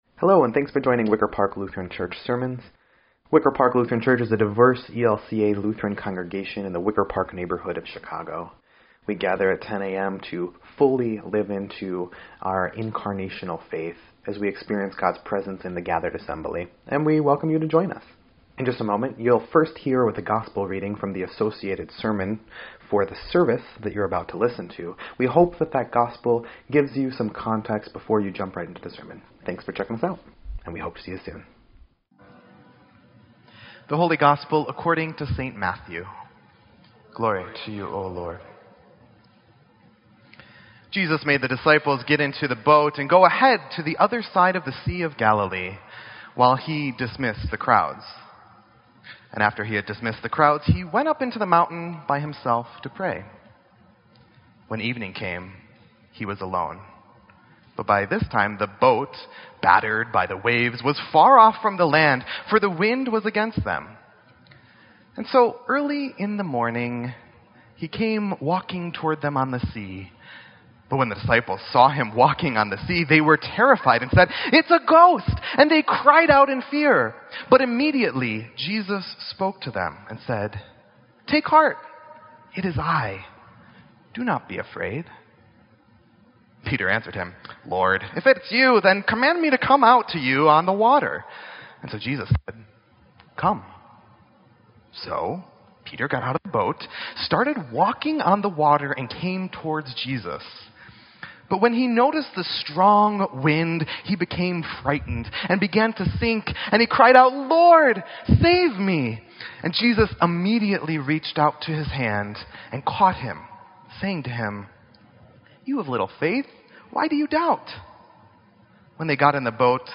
Wicker Park Lutheran Church
Sermon_8_13_17_EDIT.mp3